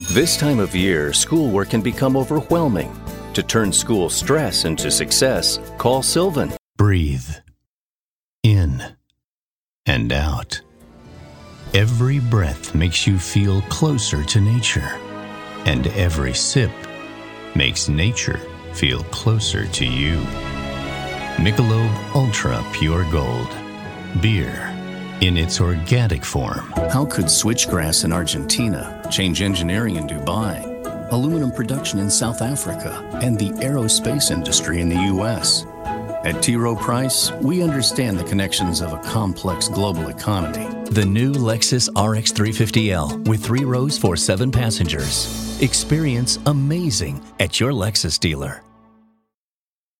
Professional Voice Over Services | Male Voice Over Artist
Looking for a professional male voice over artist?